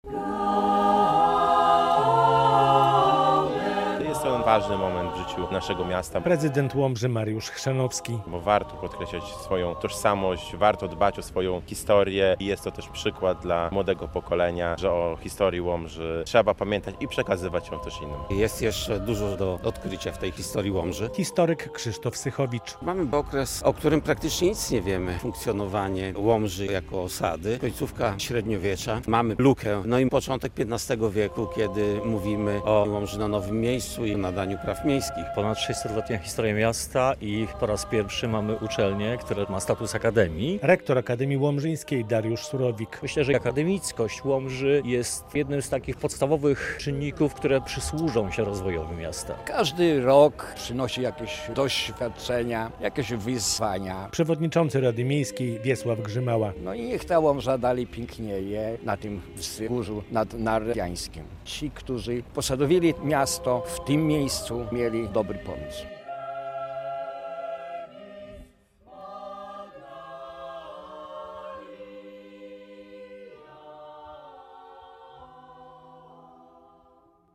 O historii i tradycji, ale także o teraźniejszości i przyszłości mówiono podczas uroczystej sesji rady miejskiej Łomży z okazji 606. rocznicy nadania praw lokacyjnych miastu.
606 lat temu Łomży nadano prawa miejskie - relacja